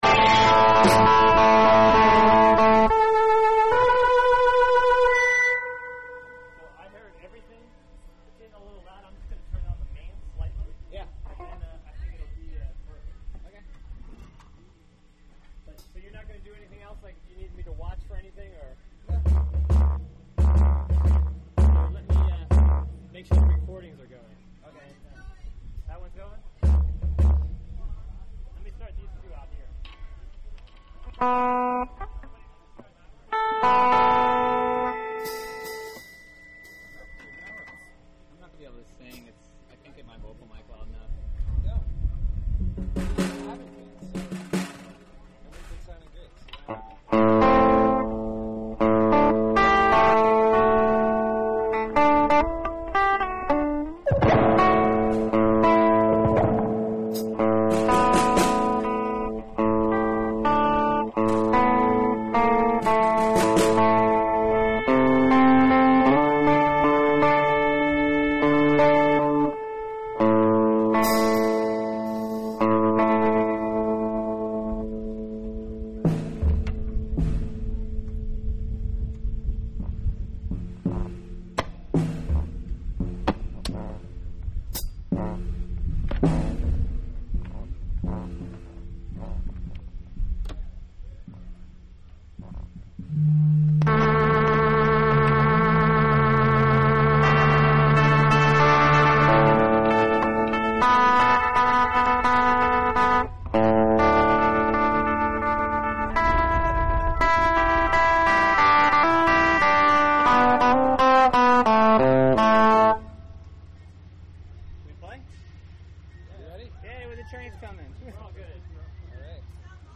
Join us Saturday for avant folk performances from...
City of Hudson, New York